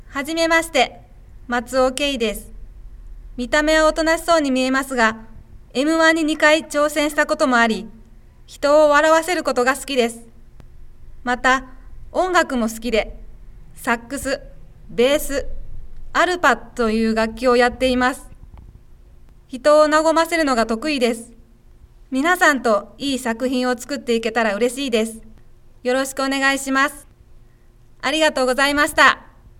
出⾝地・⽅⾔ 大阪府・関西弁
ボイスサンプル